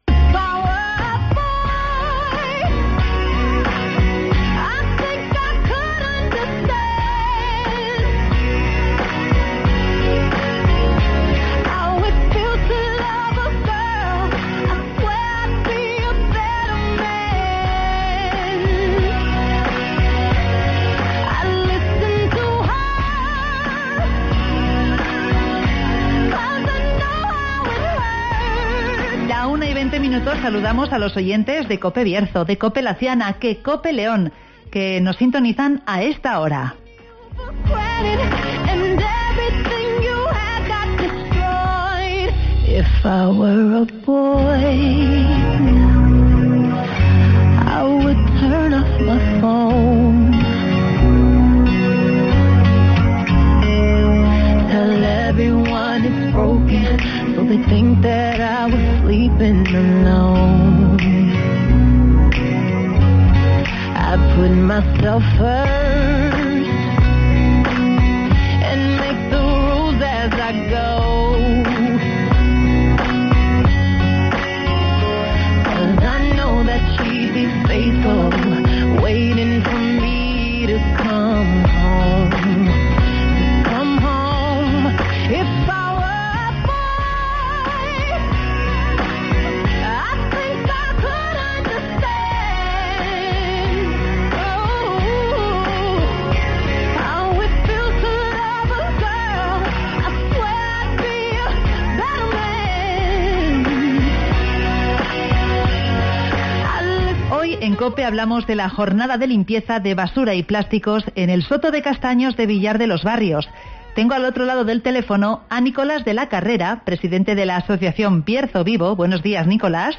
Proyecto Orbanajo y Bierzo Vivo limpiarán el soto de castaños de Villar este sábado 21 de noviembre (Entrevista